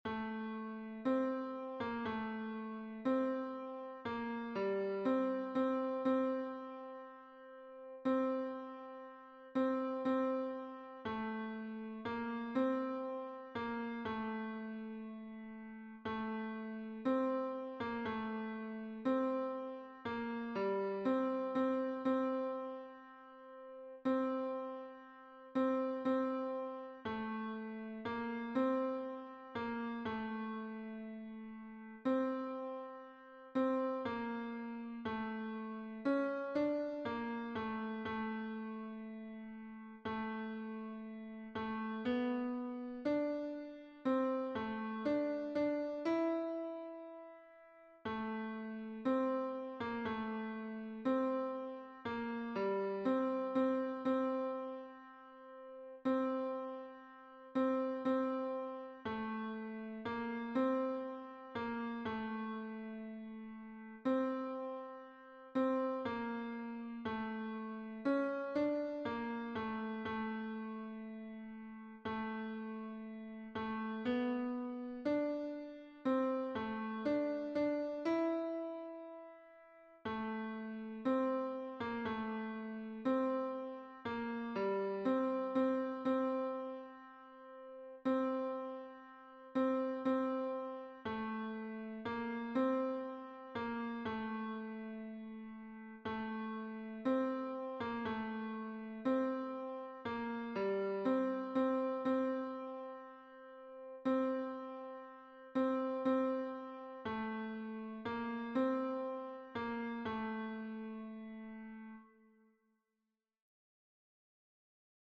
- Œuvre pour chœur à 4 voix mixtes (SATB)
Tenor